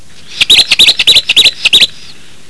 Completo (797 Kb)), ave palustre y, posiblemente otro, un ermitaño misterioso de la floresta austral, el Colilarga (Sylviorrhorhynchus desmursii) (canto:
c_colilarga.wav